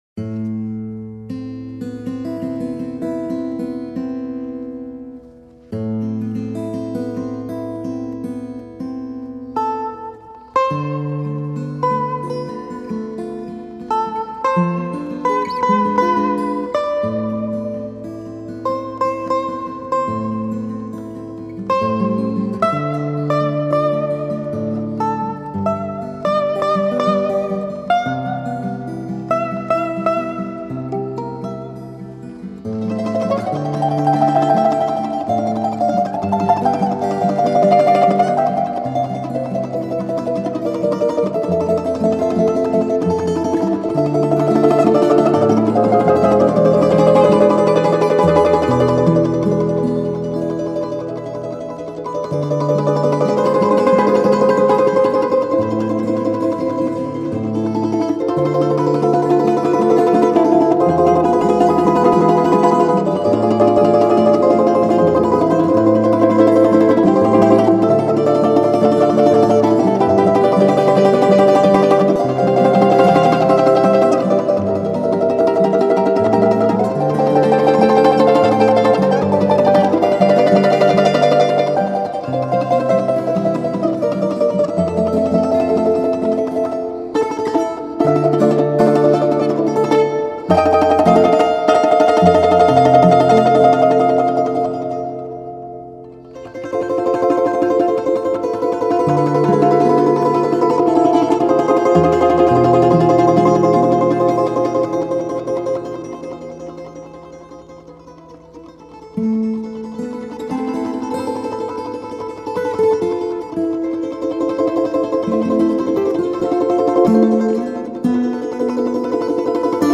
Инструмент. дуэт